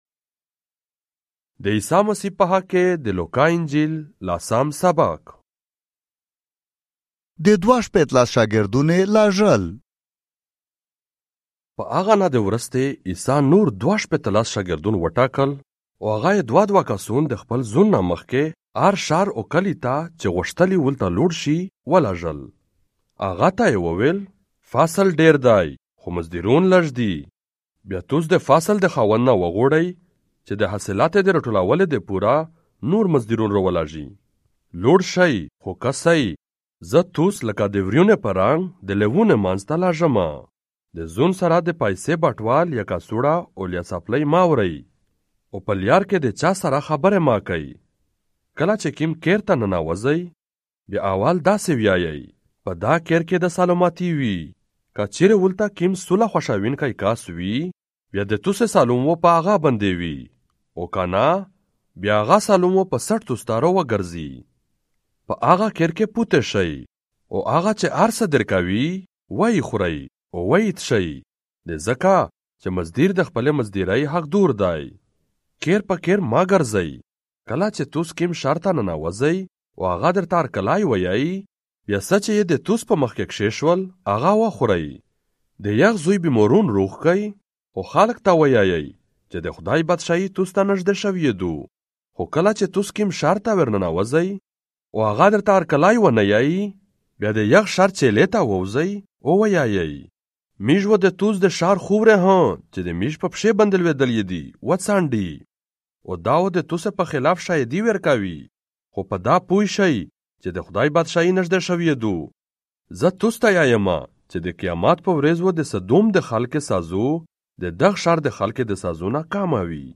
Luke - Chapter 10 in the Pashto language Injil, Central - audio 2025